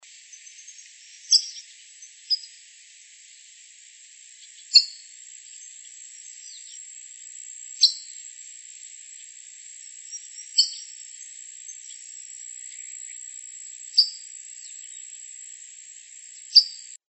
Sooty Tyrannulet (Serpophaga nigricans)
Spanish Name: Piojito Gris
Sex: Male
Life Stage: Adult
Location or protected area: Villa Paranacito
Condition: Wild
Certainty: Photographed, Recorded vocal